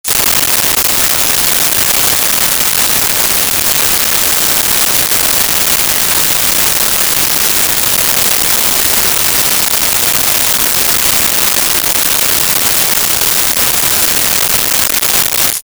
Bats
Bats.wav